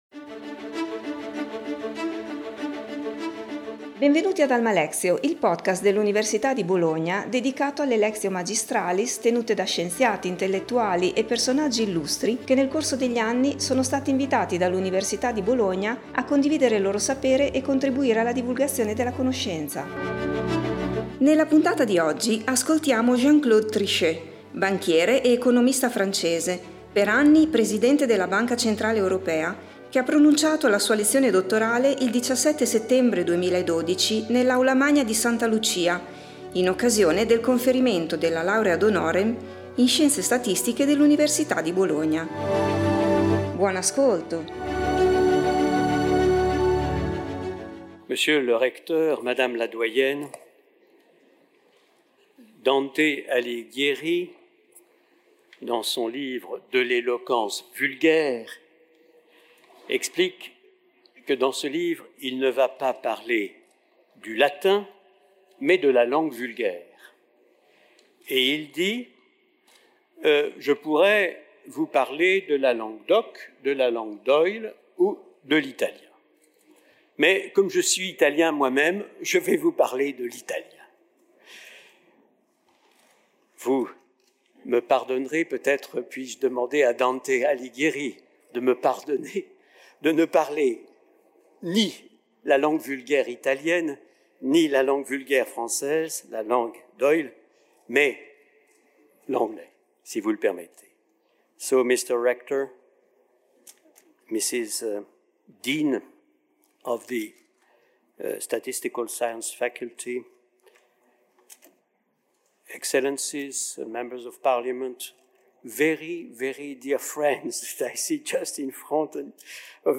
Jean Claude Trichet, banchiere ed economista francese, per anni presidente della Banca centrale europea, ha pronunciato la sua lezione dottorale il 17 settembre 2012 nell’Aula magna di Santa Lucia in occasione del conferimento della Laurea honoris causa in Scienze statistiche dell'Università di Bologna.